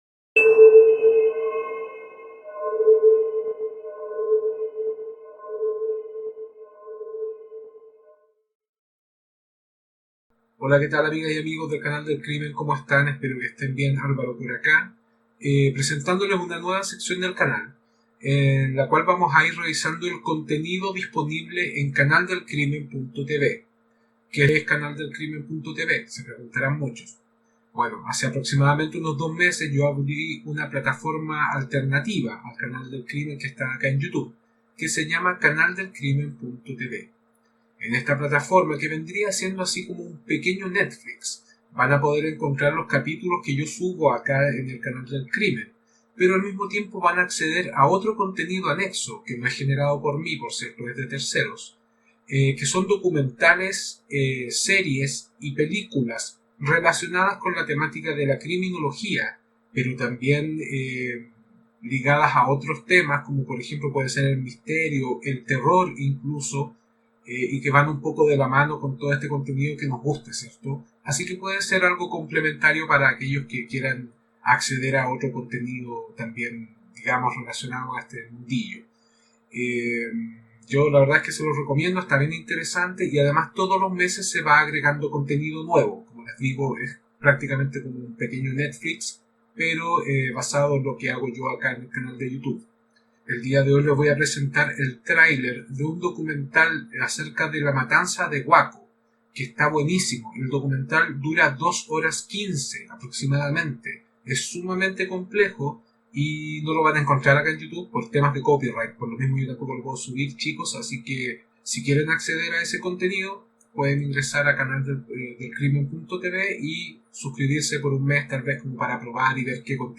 Escuchar True Crime Latino comienza con una decisión consciente: todos los anuncios están colocados al inicio para no romper la experiencia, para no sacarte de la atmósfera cuando la historia ya te tiene atrapado.